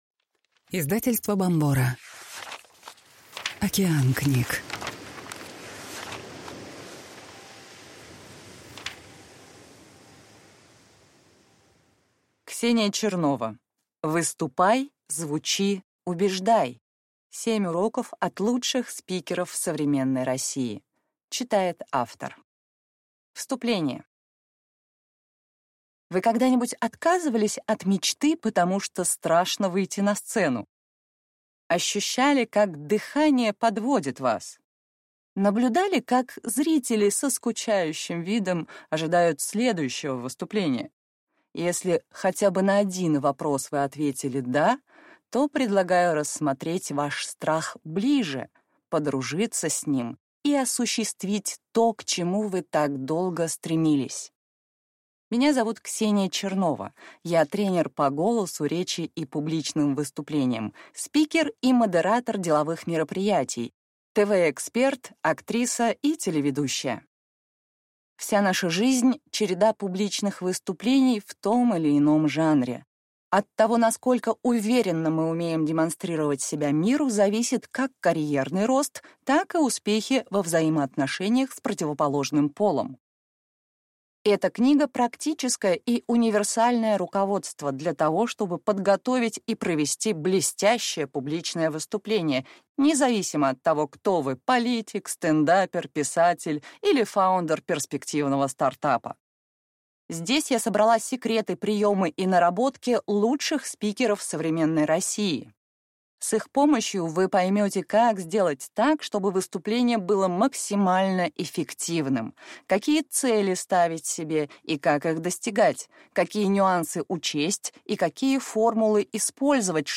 Аудиокнига Выступай. Звучи. Убеждай. 7 уроков от лучших спикеров современной России | Библиотека аудиокниг